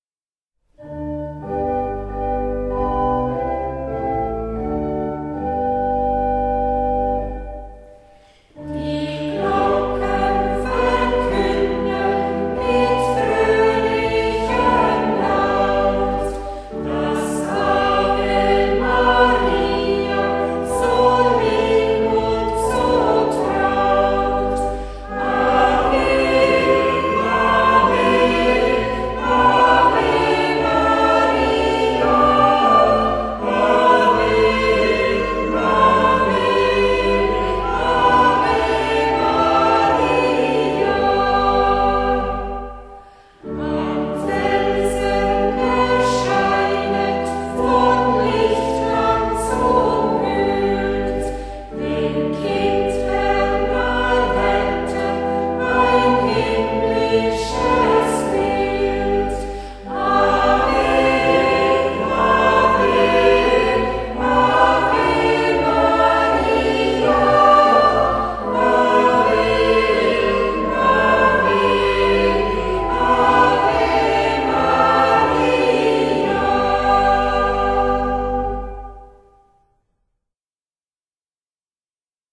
DAS GLOCKENGELÄUTE VON LOURDES